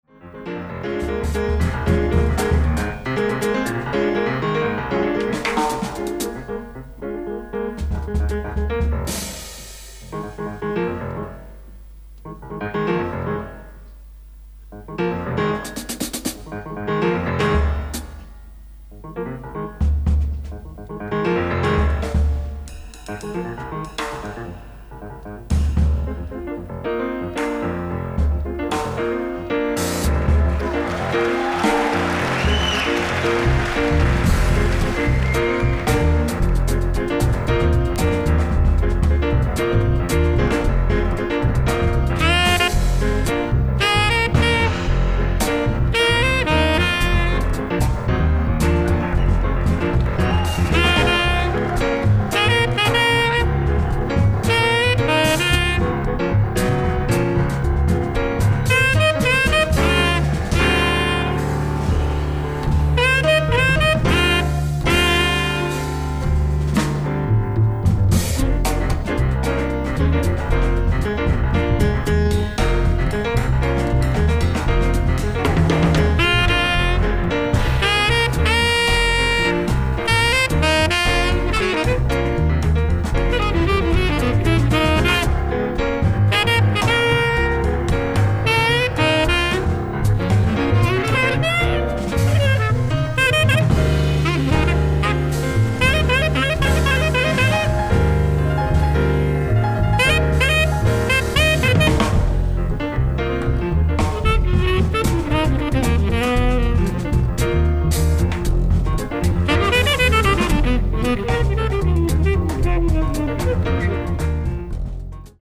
ライブ・アット・ロンドン、イングランド 07/02/2004
※試聴用に実際より音質を落としています。